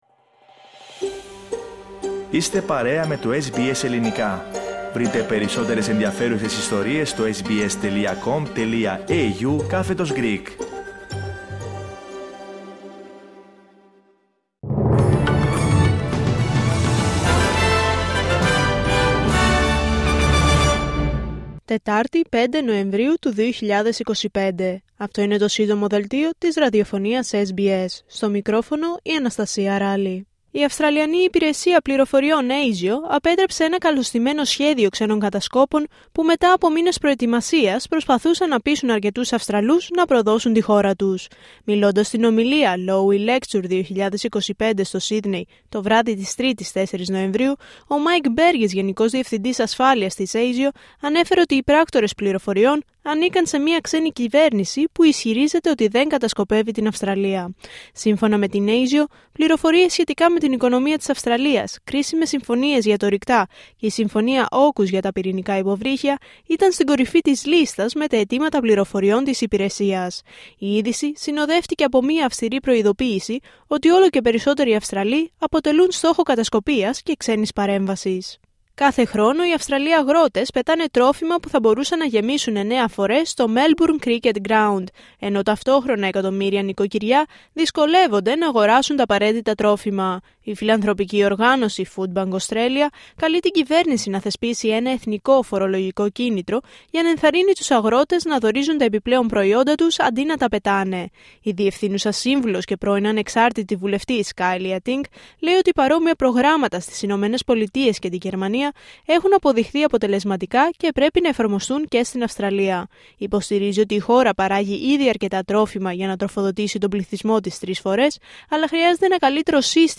H επικαιρότητα έως αυτή την ώρα στην Αυστραλία, την Ελλάδα, την Κύπρο και τον κόσμο στο Σύντομο Δελτίο Ειδήσεων της Τετάρτης 5 Νοεμβρίου 2025.